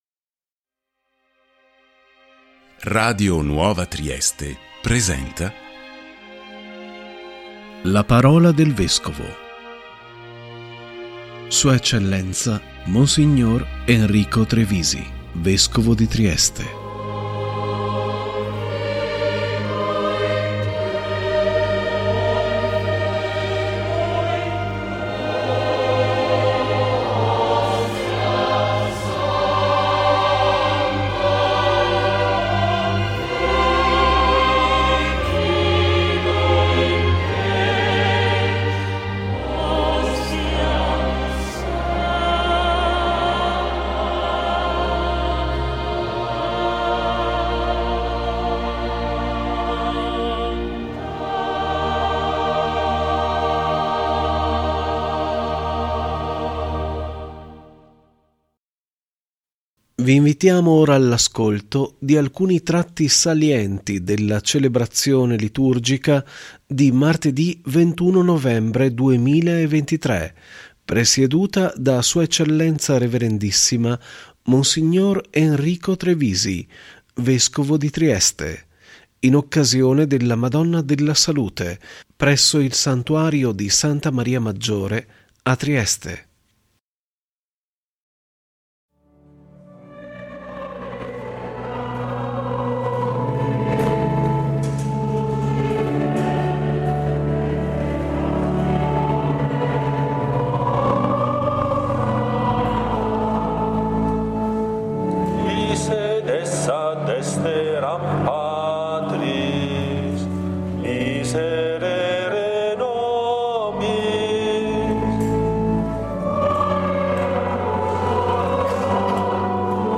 ♦ si è tenuta, Martedì 21 novembre 2023, la liturgia eucaristica presieduta da Sua Eccellenza Rev.issima Mons. Enrico Trevisi, Vescovo di Trieste, in occasione della Madonna della Salute presso il Santuario di Santa Maria Maggiore a Trieste.